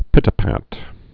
(pĭtə-păt)